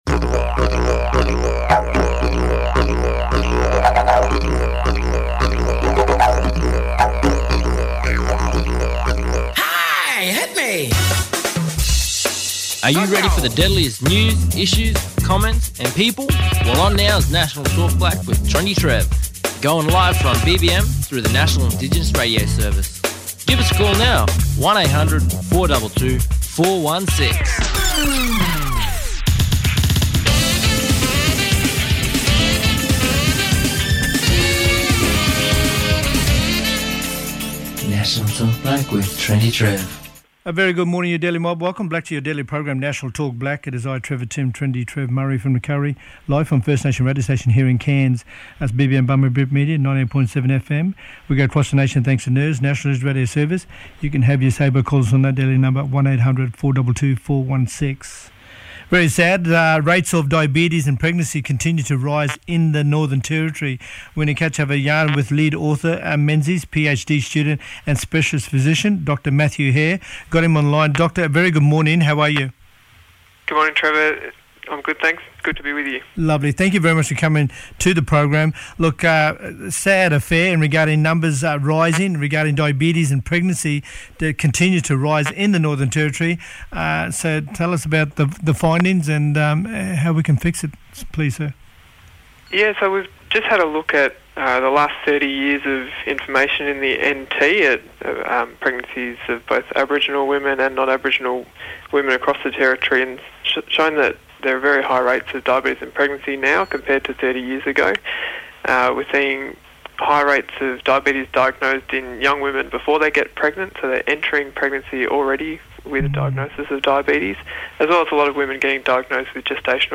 Information Commissioner from the Office of the Australian Information Commissioner Rachael Rangihaeata discussing their audit report on privacy and public data. Natalie Lewis, Queensland Family and Child Commission Commissioner talking about how mob are encouraged to keep supporting eachother through parent-to-parent connection.